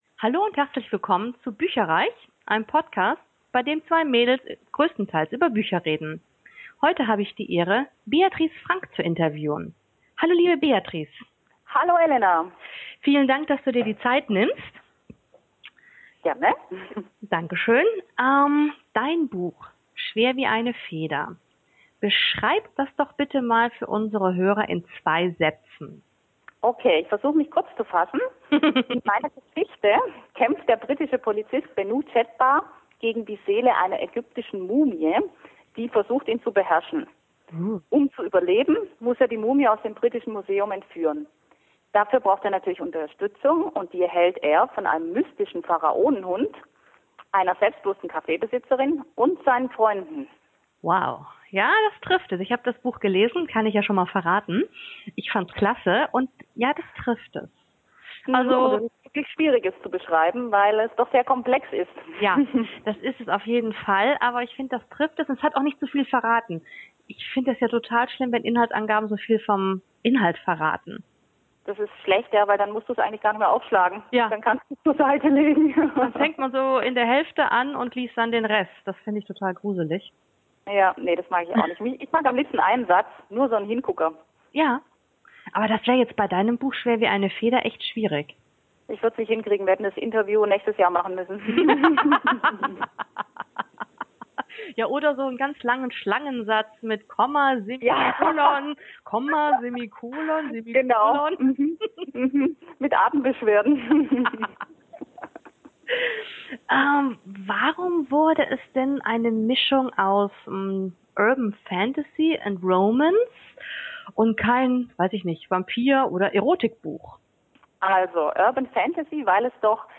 Willkommen bei bücherreich, unserem Bücher-Podcast!